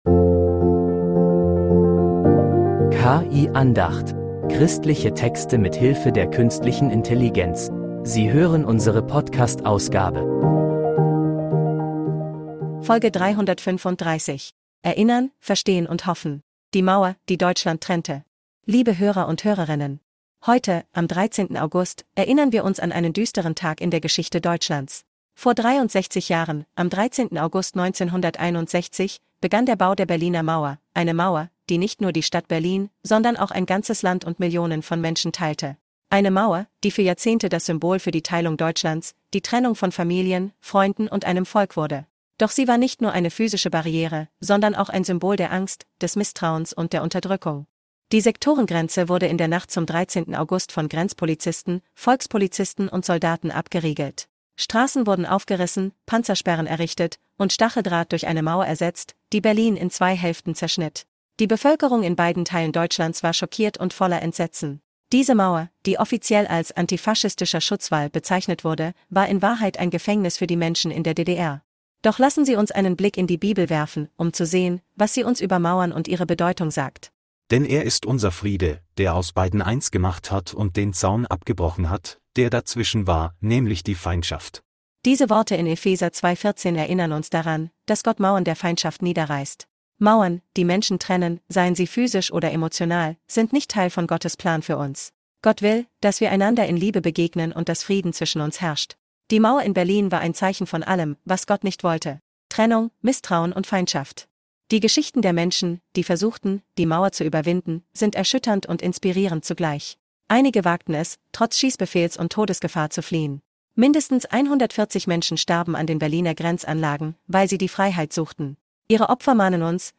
Diese Andacht spricht über Mauern,